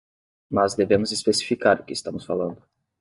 Pronounced as (IPA)
/is.pe.si.fiˈka(ʁ)/